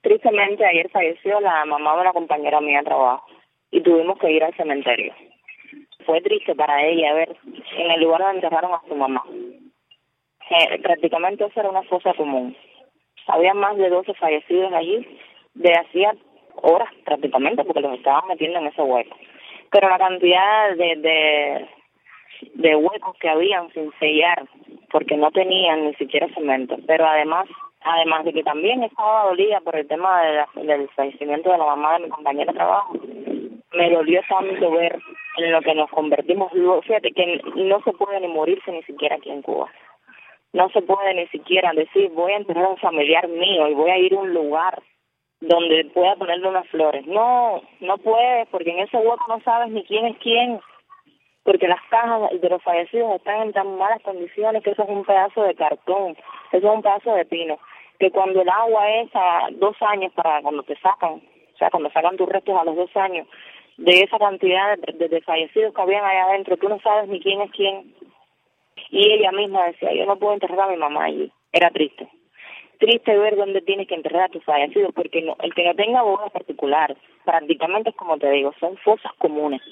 Testimonio desde Camagüey revela impacto humano de muertes por arbovirosis en Cuba